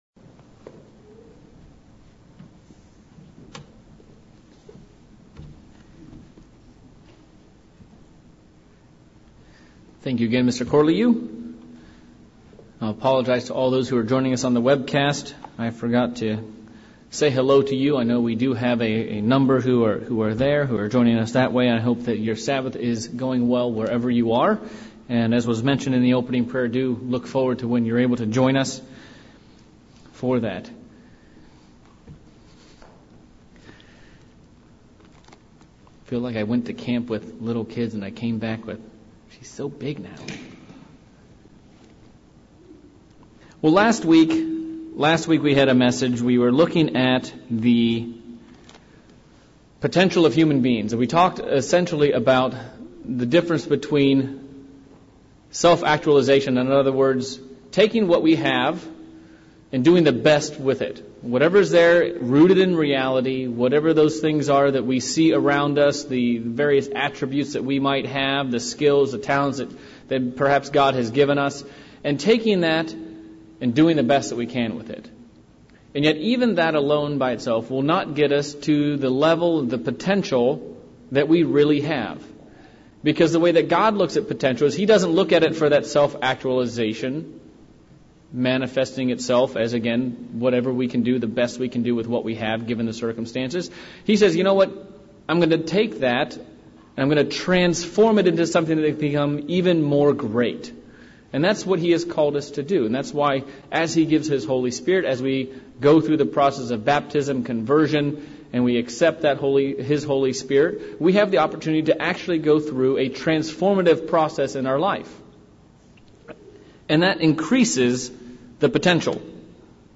Part 1 of a 3 part sermon series on what is our purpose in life? Did God just put us here for no reason in particular or did he have a much grander purpose in mind?